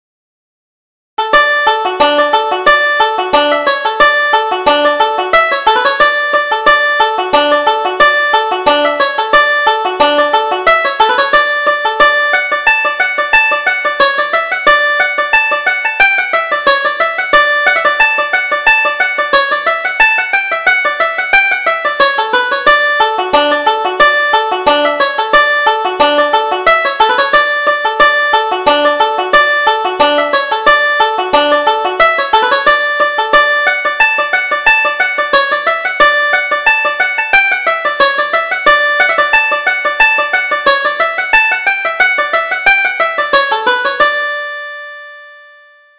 Reel: The Tent at the Fair